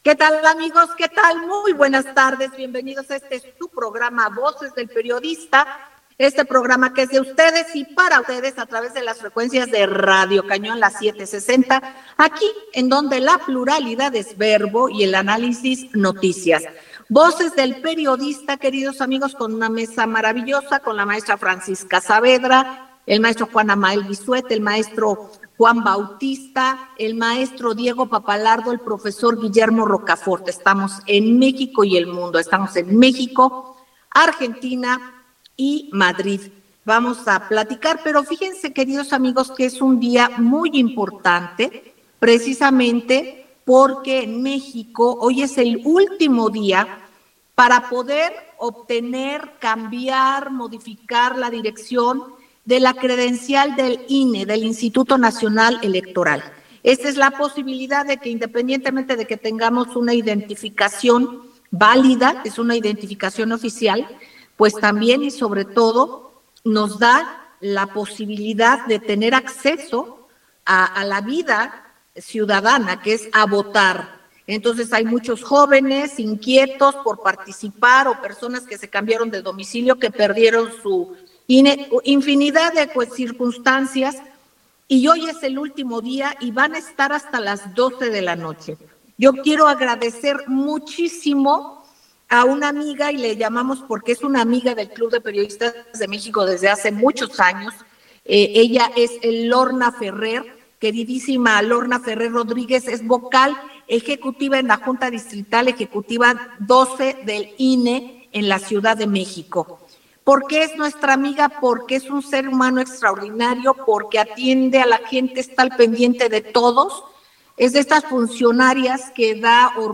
Panel: